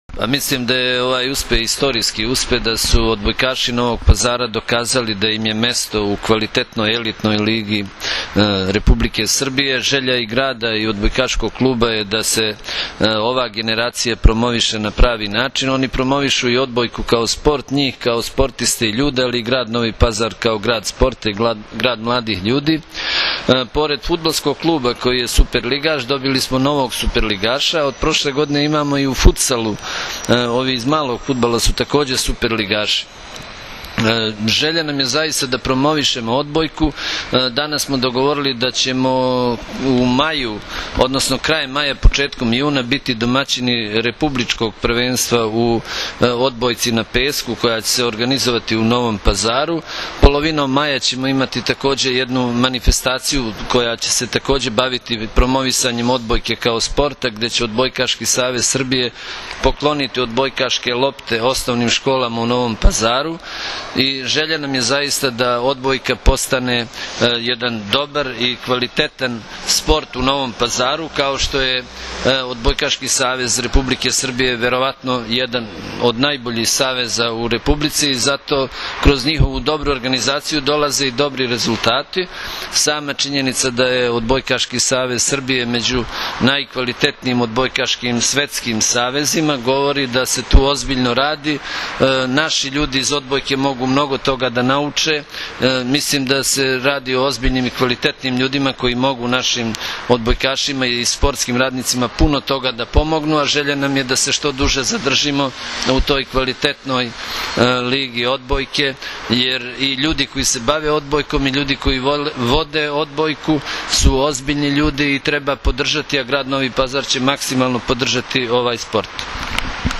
IZJAVA DR MEHA MAHMUTOVIĆA